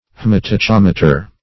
Search Result for " haematachometer" : The Collaborative International Dictionary of English v.0.48: Haematachometer \H[ae]m`a*ta*chom"e*ter\ (-t[.a]*k[o^]m"[-e]*t[~e]r), n. [H[ae]ma- + Gr. tachy`s swift + -meter.]
haematachometer.mp3